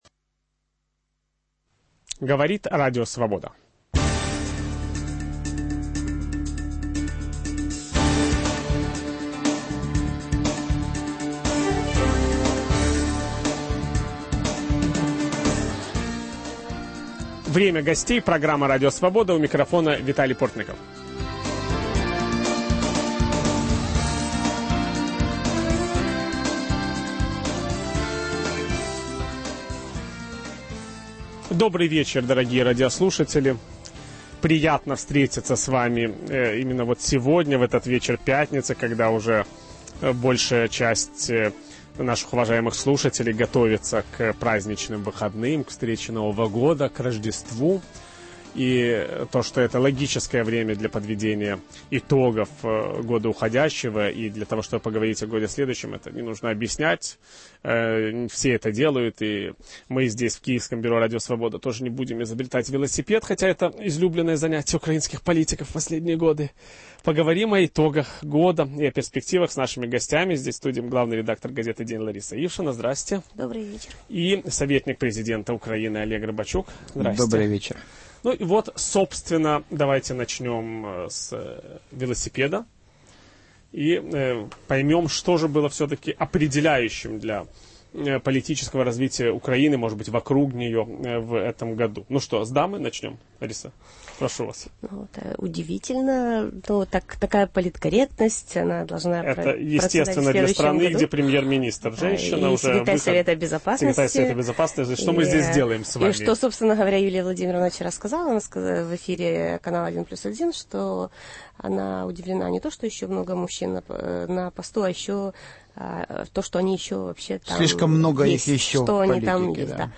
Итоги украинского политического года ведущий программы Виталий Портников подводит вместе с советником президента Украины Олегом Рыбачуком и главным редактором газеты "День" Ларисой Ившиной